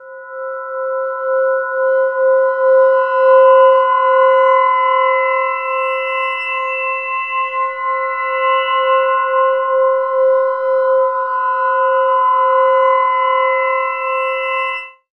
Index of /90_sSampleCDs/Chillout (ambient1&2)/11 Glass Atmos (pad)